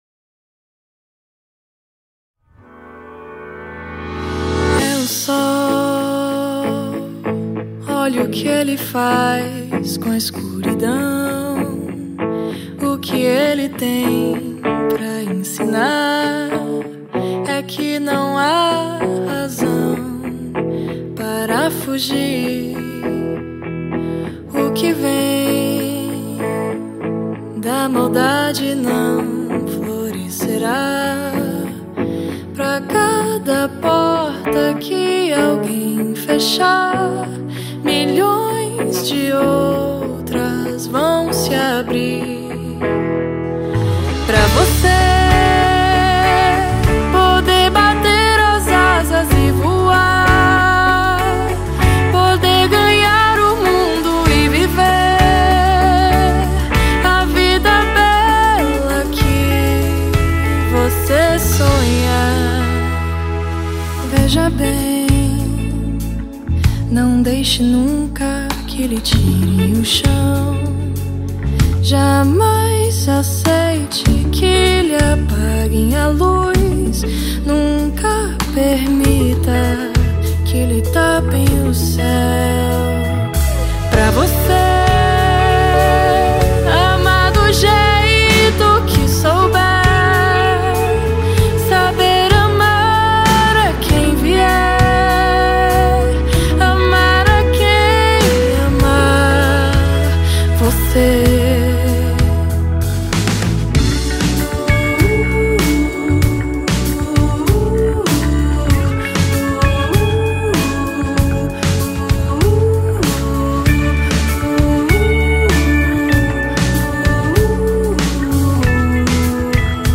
cantora e compositora baiana